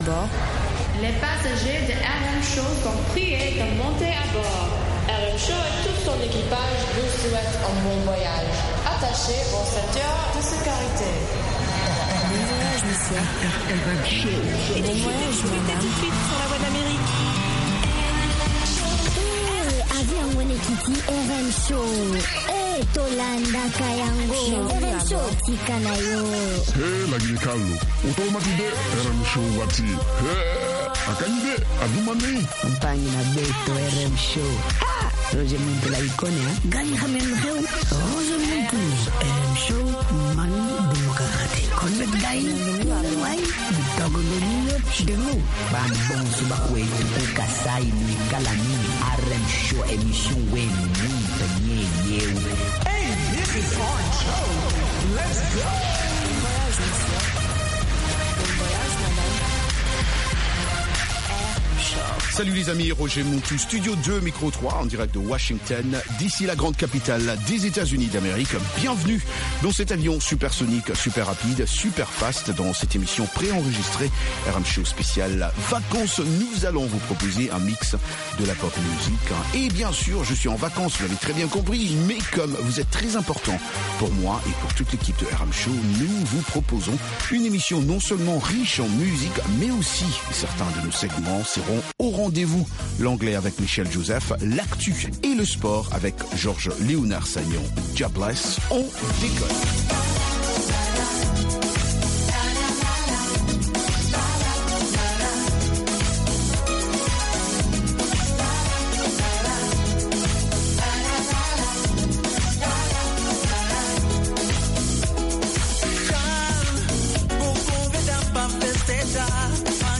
Zouk, Reggae, Latino, Soca, Compas et Afro